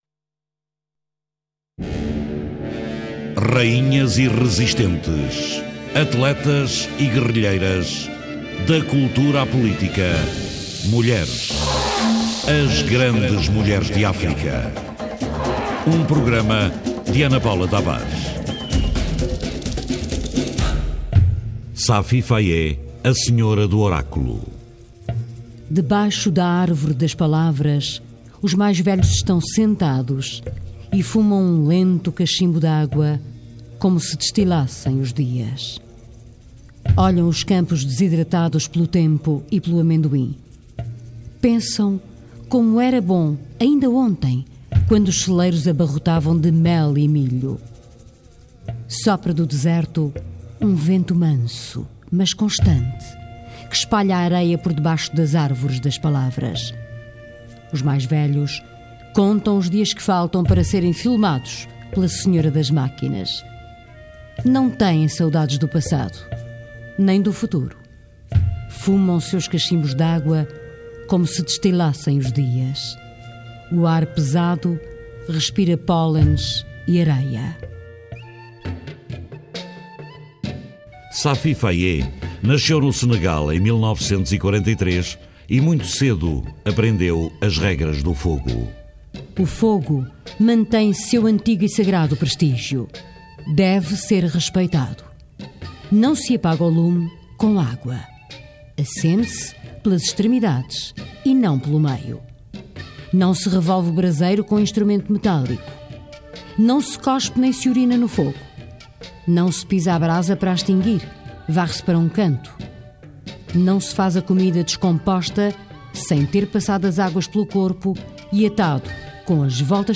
Entrevista a Alice Vieira
Entrevista a Alice Vieira, escritora, a propósito da sua nova obra "Só Duas Coisas que Entre Tantas me Afligiram".